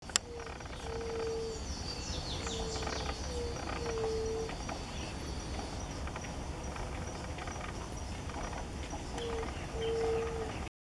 Yerutí Común (Leptotila verreauxi)
Nombre en inglés: White-tipped Dove
Localidad o área protegida: Reserva Ecológica Costanera Sur (RECS)
Condición: Silvestre
Certeza: Vocalización Grabada
canto-de-yeruti.mp3